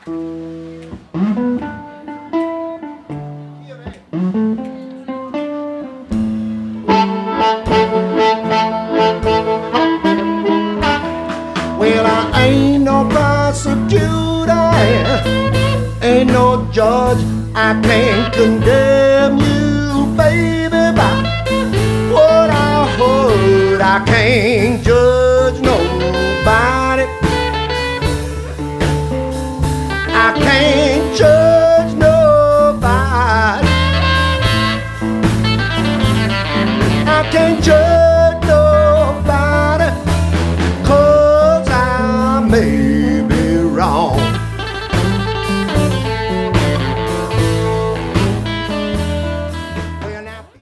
recorded live at Moe's Alley in Santa Cruz, California